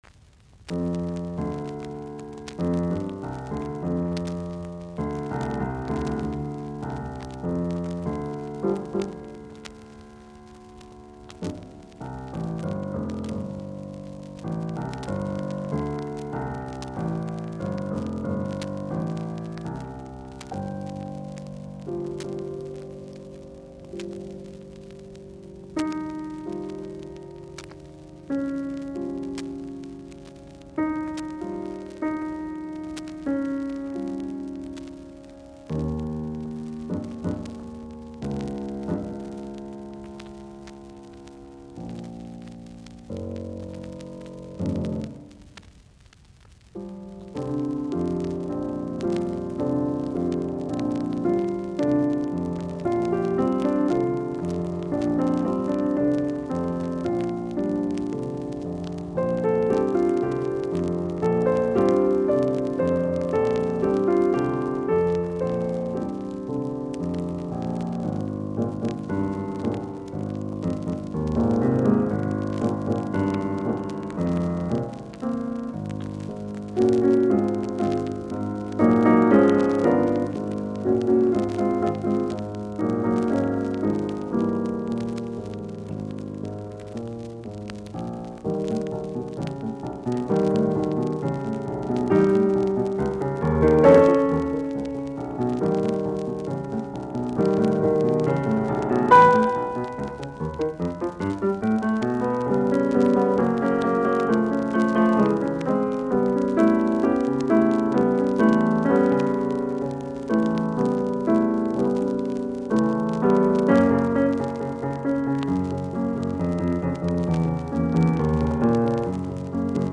Soitinnus : Piano